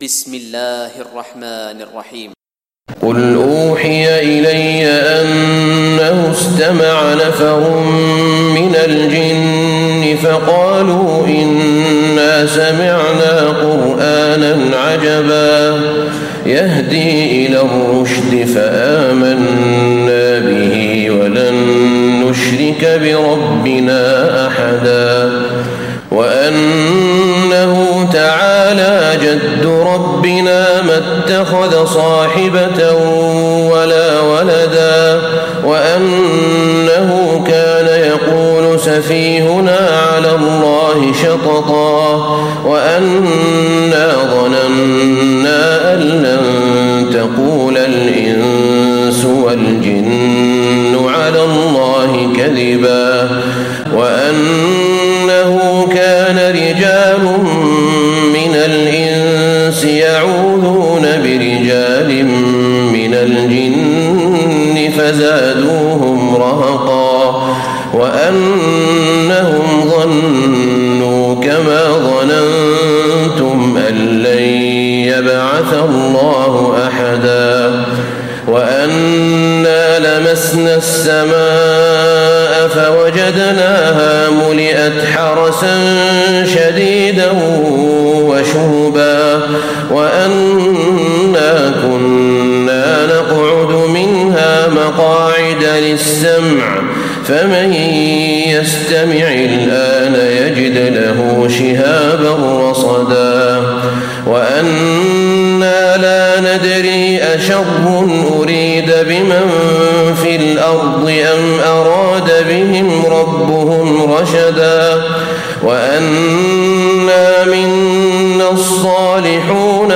تراويح ليلة 28 رمضان 1436هـ سورة الجن Taraweeh 28 st night Ramadan 1436H from Surah Al-Jinn > تراويح الحرم النبوي عام 1436 🕌 > التراويح - تلاوات الحرمين